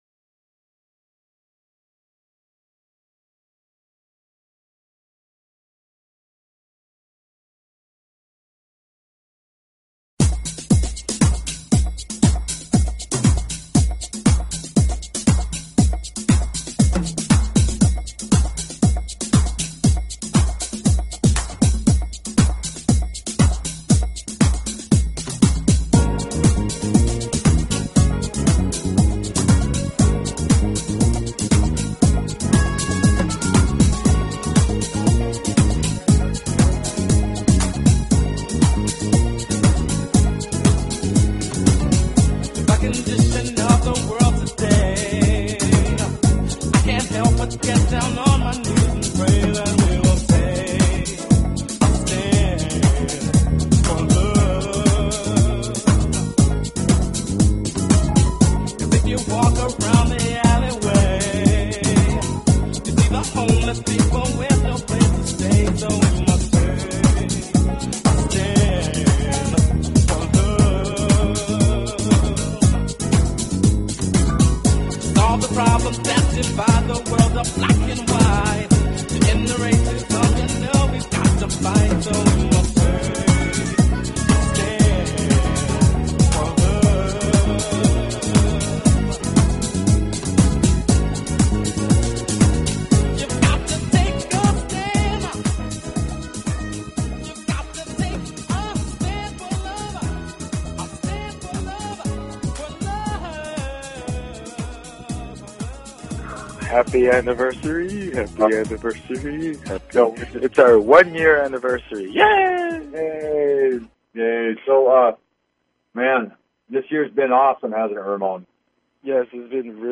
Talk Show Episode, Audio Podcast, The_Hundredth_Monkey_Radio and Courtesy of BBS Radio on , show guests , about , categorized as
We will be taking some calls throughout the show so have your questions ready
Open lines for questions.